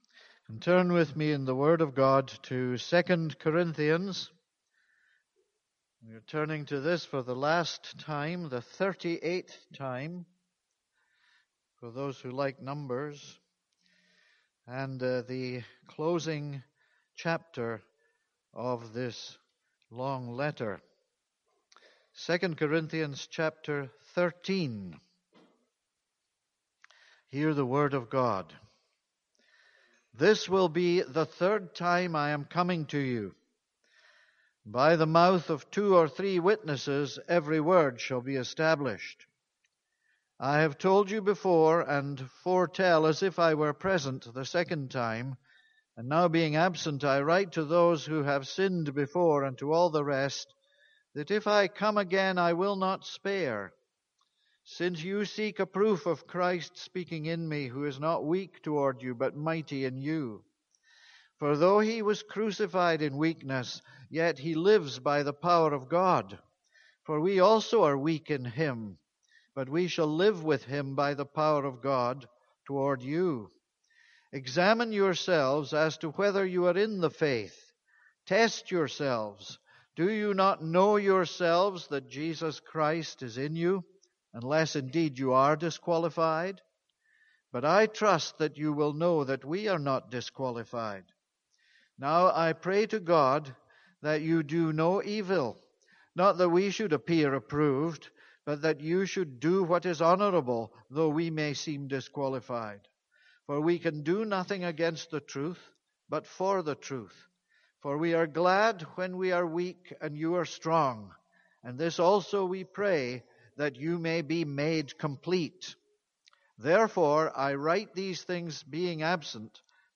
This is a sermon on 2 Corinthians 13.